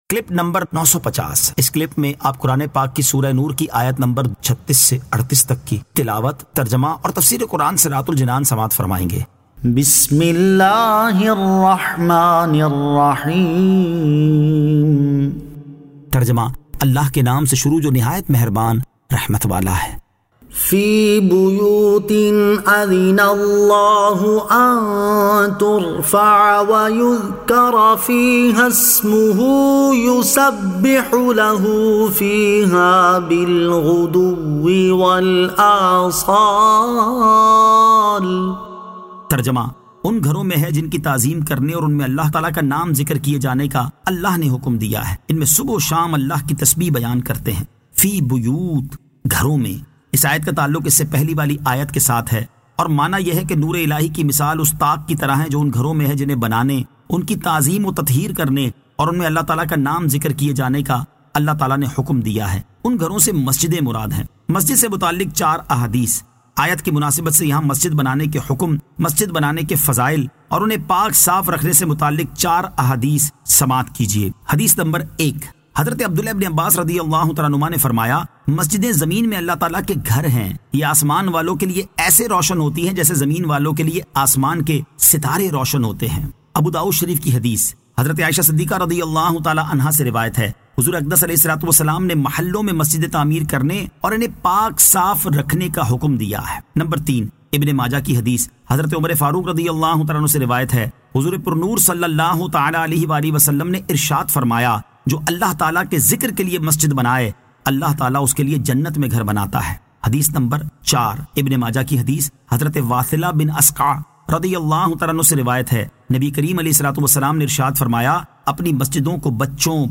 Surah An-Nur 36 To 38 Tilawat , Tarjama , Tafseer